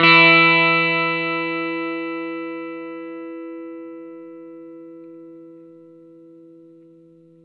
R12NOTE E+.A.wav